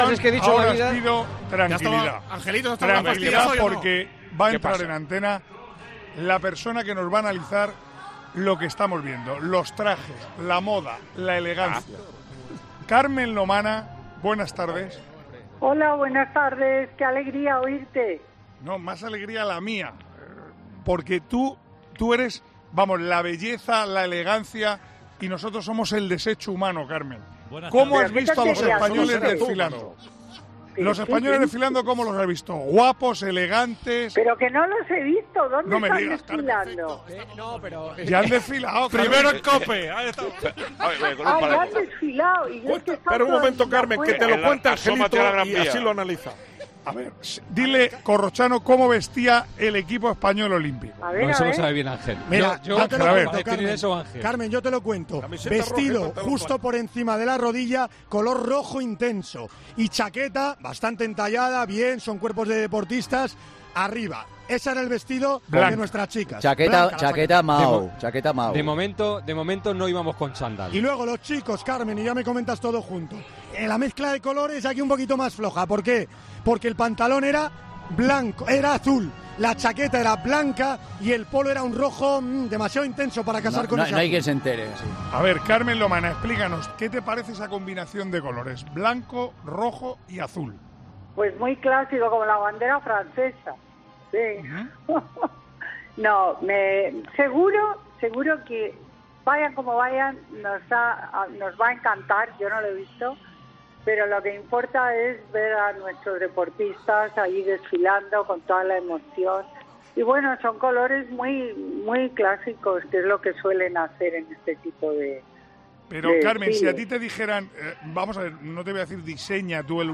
Carmen Lomana ha hablado este viernes en directo para opinar sobre la vestimenta de los deportistas españoles durante la Ceremonia Inaugural de los...